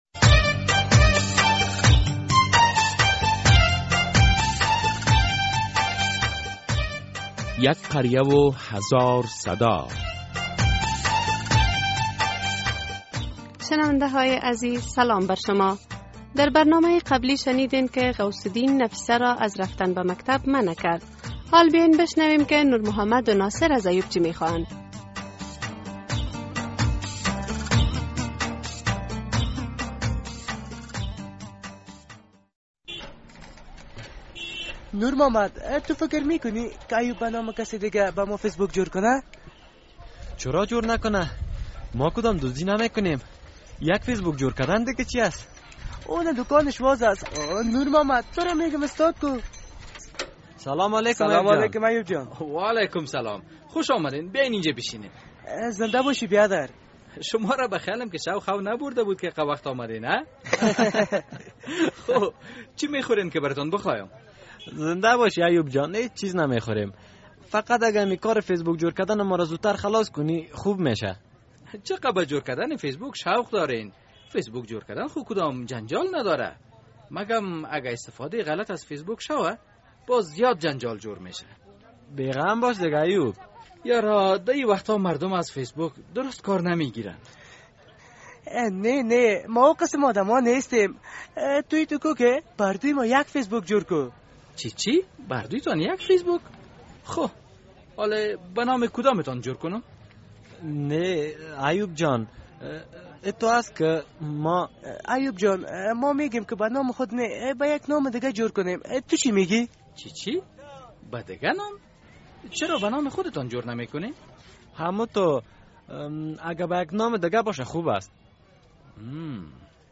در این درامه که موضوعات مختلف مدنی، دینی، اخلاقی، اجتماعی و حقوقی بیان می‌گردد هر هفته به روز های دوشنبه ساعت ۳:۳۰ عصر از رادیو آزادی نشر می‌گردد...